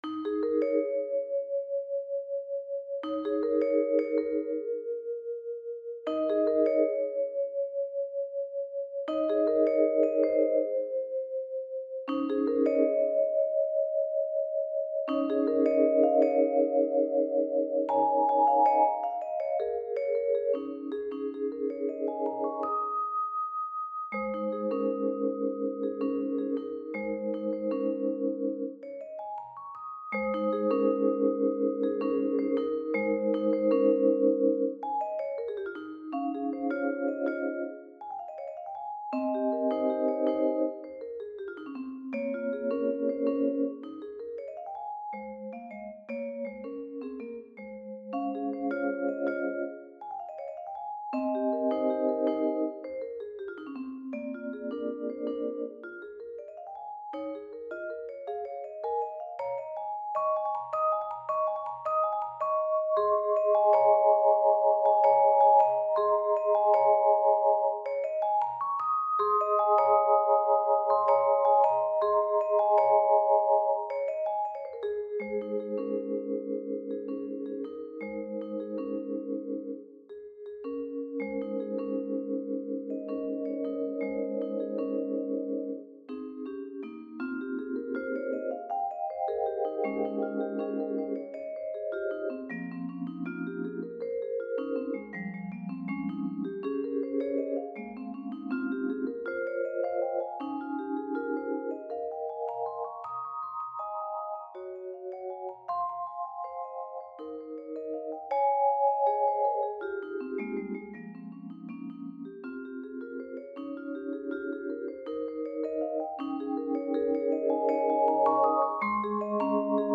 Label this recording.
Voicing: Vibraphone